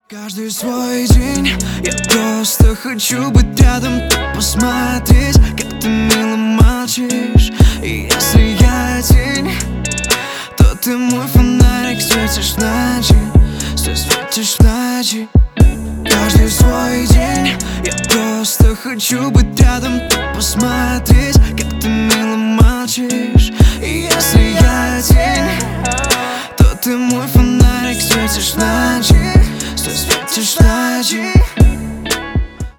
Stereo
Поп